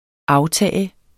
Udtale [ ˈɑwˌtæˀ ]